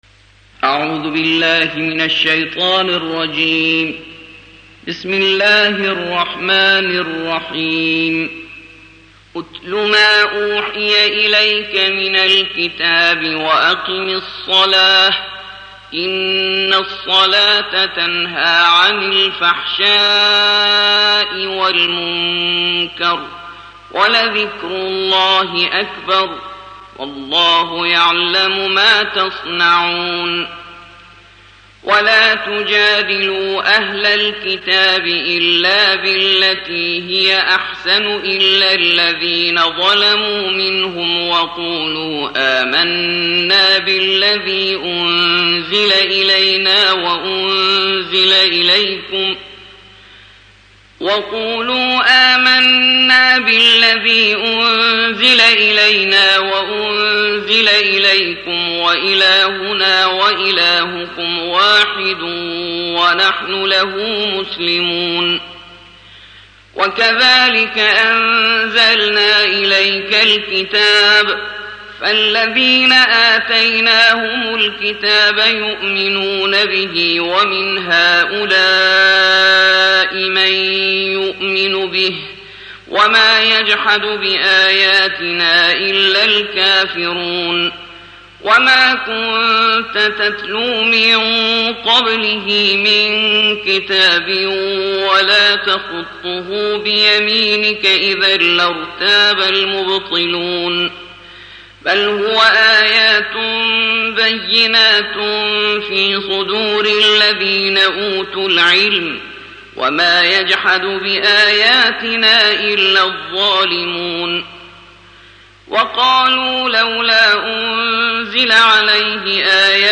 الجزء الحادي والعشرون / القارئ